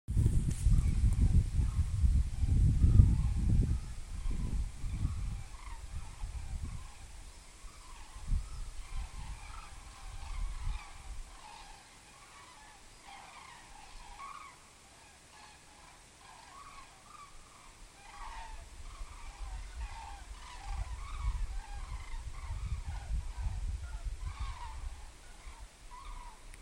Birds -> Cranes ->
Common Crane, Grus grus
StatusVoice, calls heard